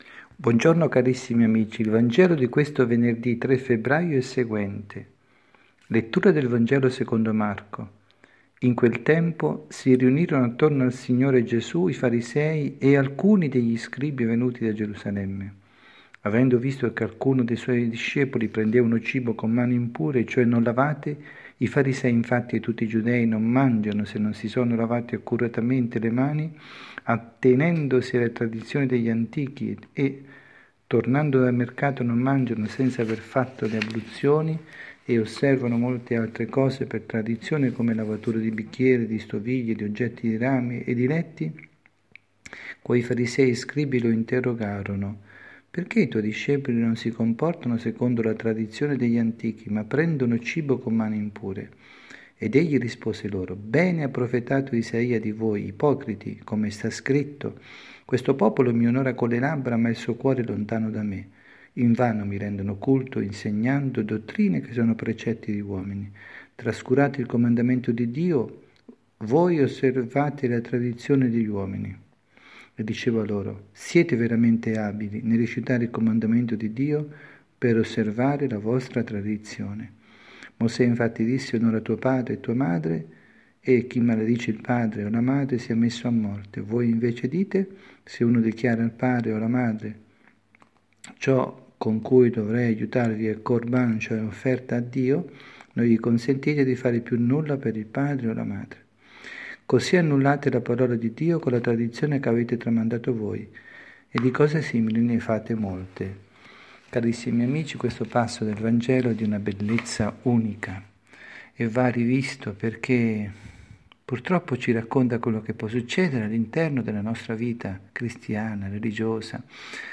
Catechesi
dalla Parrocchia S. Rita, Milano Vangelo del Rito Ambrosiano